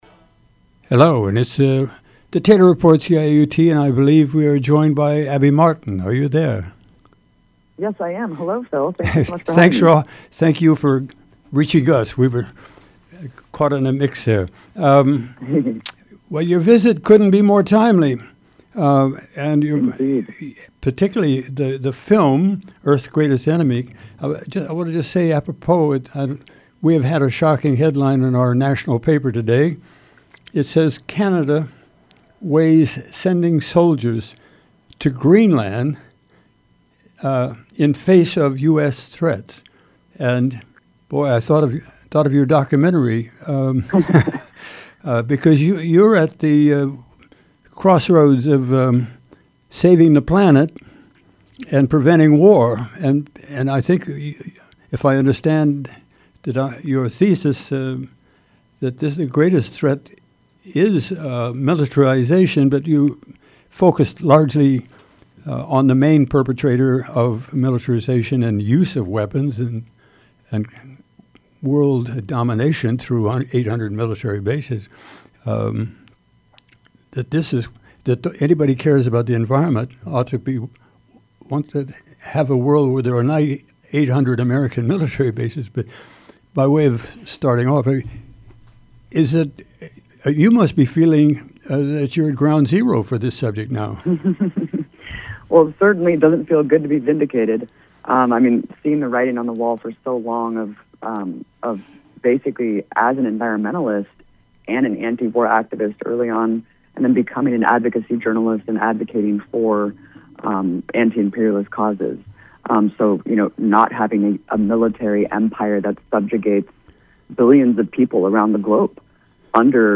Interview with Abby Martin